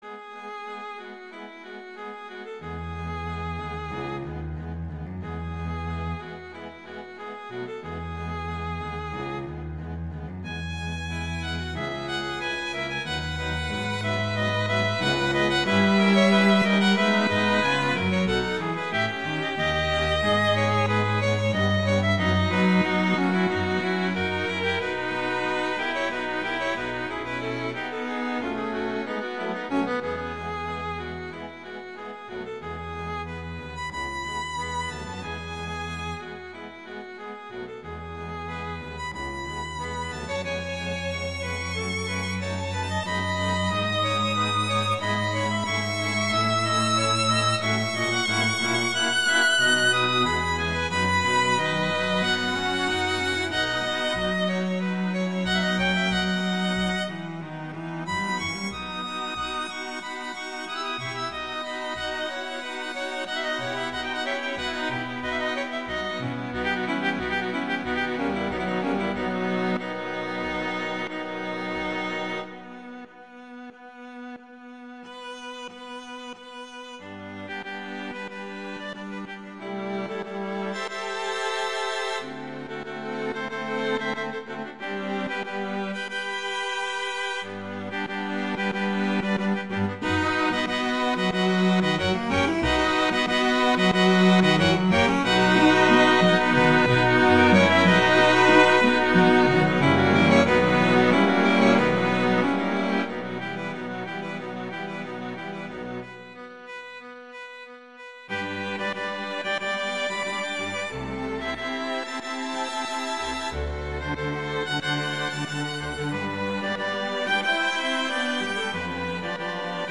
classical
E major
♩=92 BPM